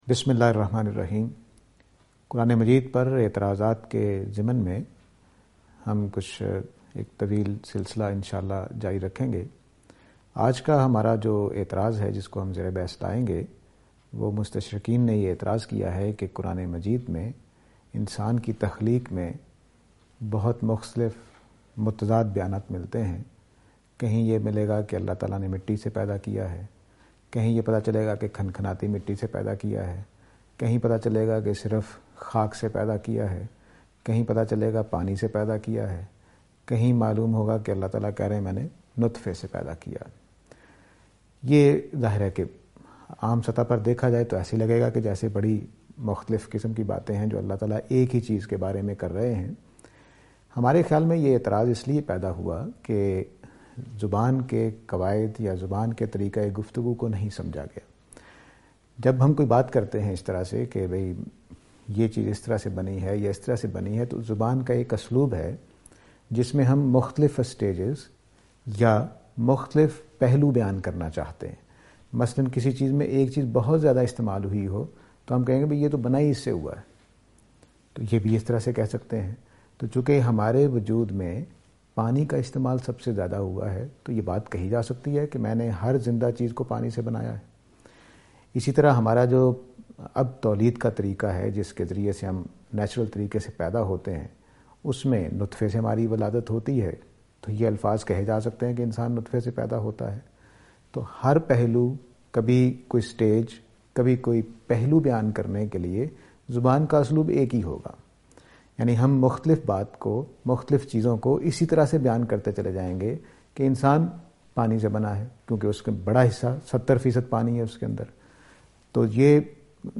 This lecture will present and answer to the allegation "How Adam was created?".